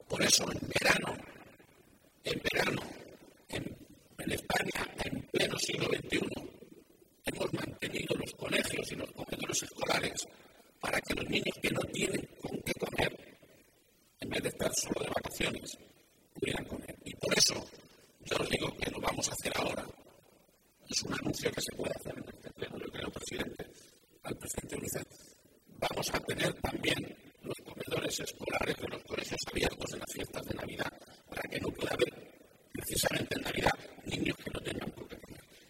El presidente de Castilla-La Mancha, Emiliano García-Page, durante la sesión plenaria de carácter extraordinario del Parlamento regional con motivo de la celebración del Día Universal de la Infancia: